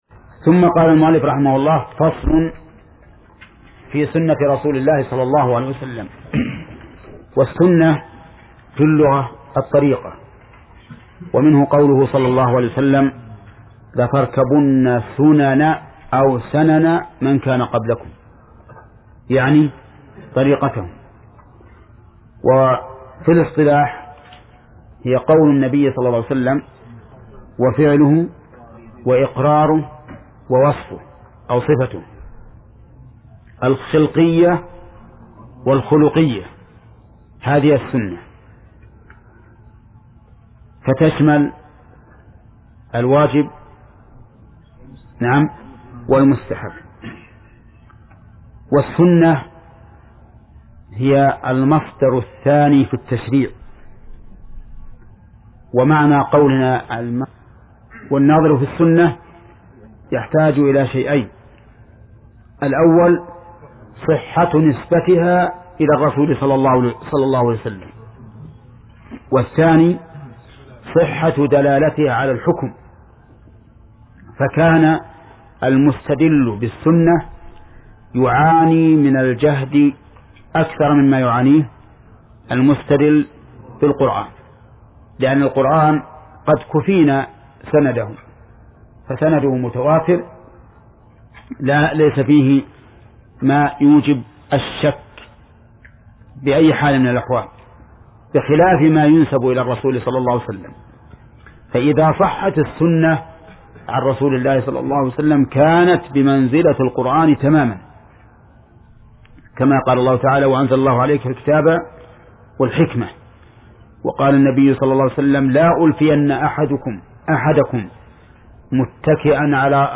بداية المجلد الثاني : درس (23) : من صفحة (5): قوله: (فصل في سنة رسول الله ﷺ، إلى صفحة (26) : قوله: (الحديث الرابع: ...).